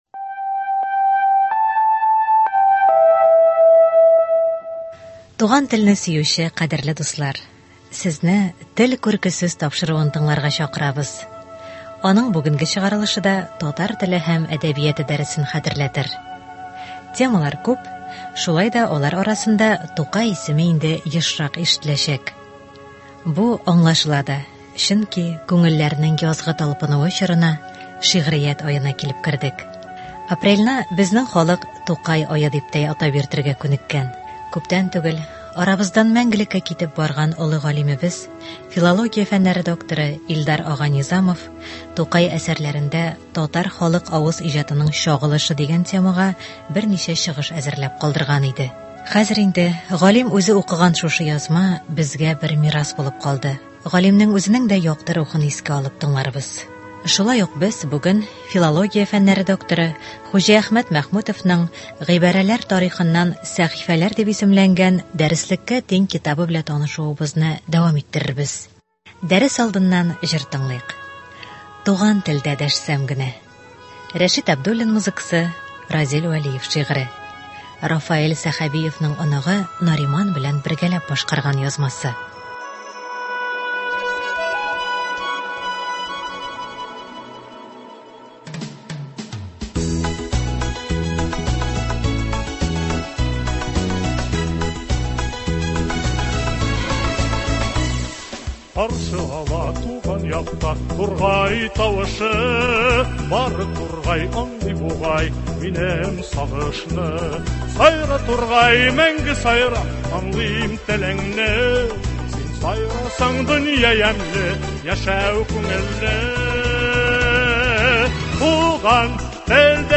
Бу тапшыруда күренекле галимнәр, язучылар халкыбызны дөрес сөйләшү, дөрес язу серләренә өйрәтә.